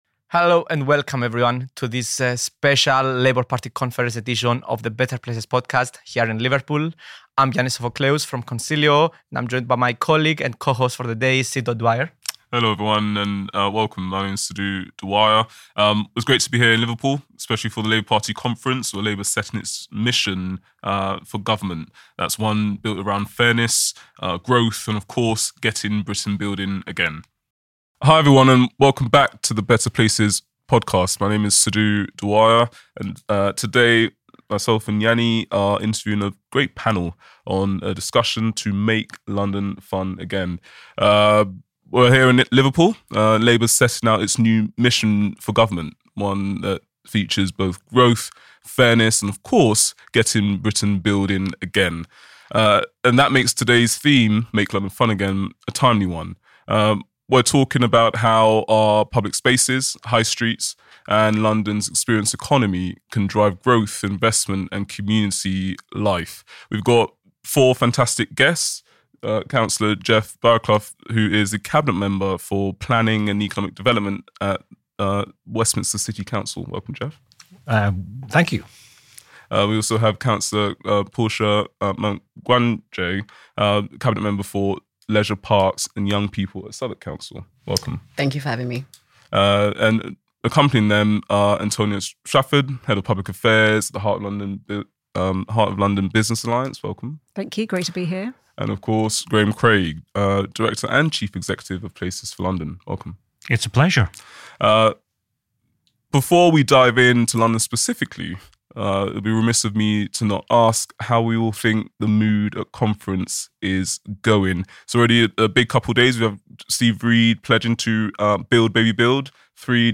At this year’s Labour Party Conference , one of the key themes was the urgent need to build more homes and restore confidence in the market. But as our panel discussed, London’s future isn’t just about housing numbers - it’s also about placemaking, culture, and the experience economy .
Don’t miss this conversation – listen now!